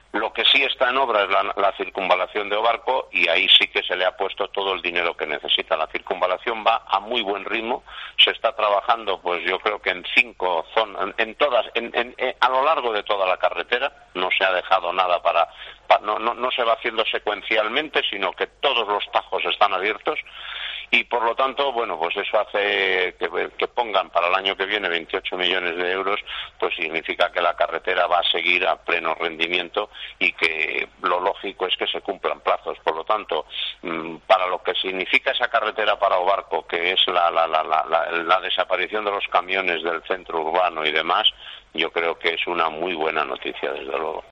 Declaraciones del alcalde de O Barco sobre la partida presupuestaria para la carretera de circunvalación